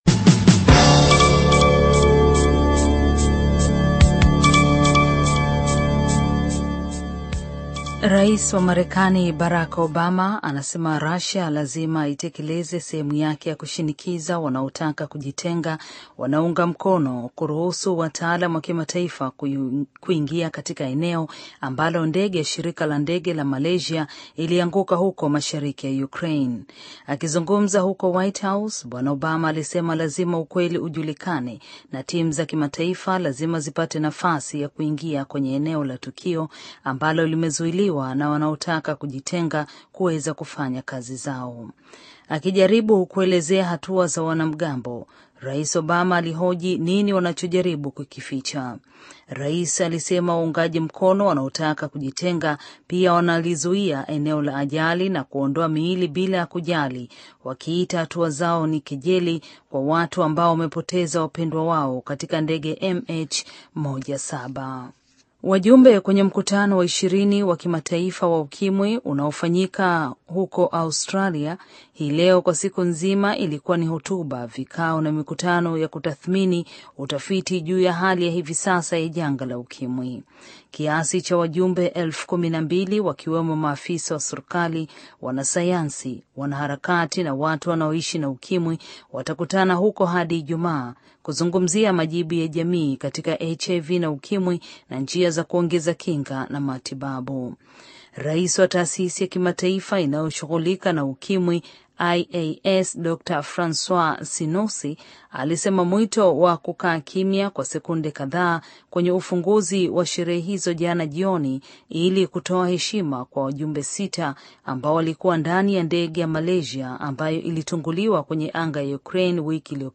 Taarifa ya Habari VOA Swahili - 5:52